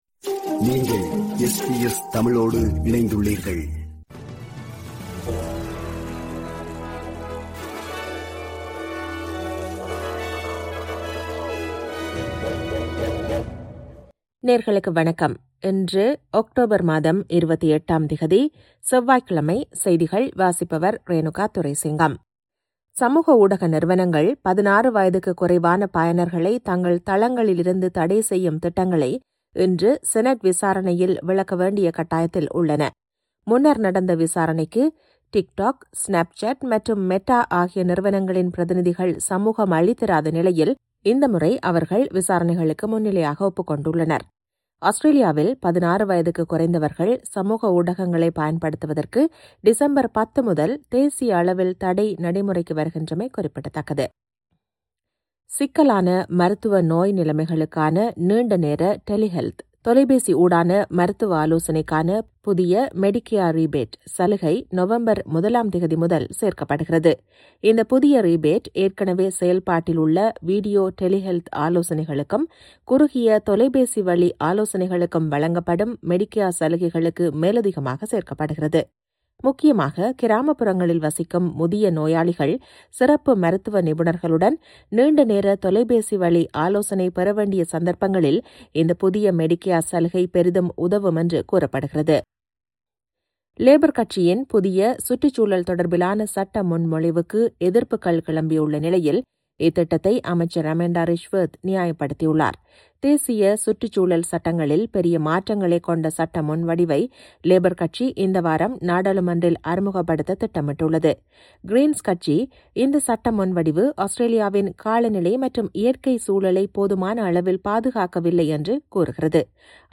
இன்றைய செய்திகள்: 28 அக்டோபர் 2025 செவ்வாய்க்கிழமை